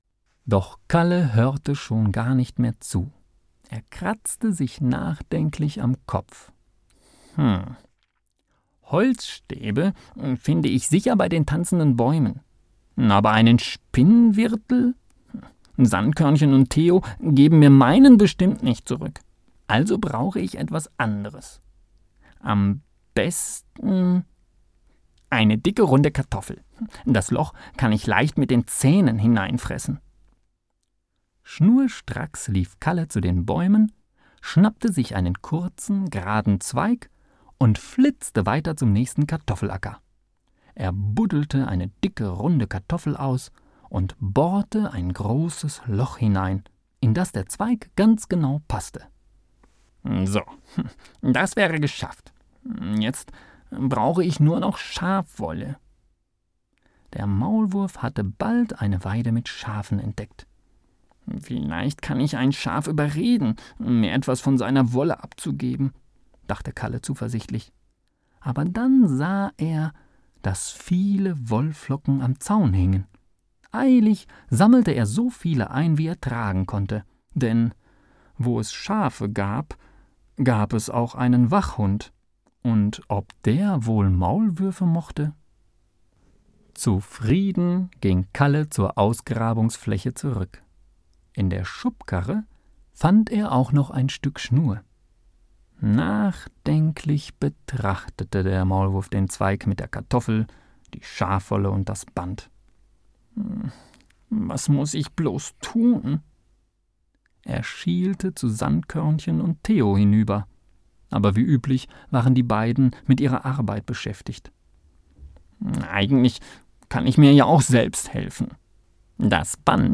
Lies mir bitte das Kapitel vor...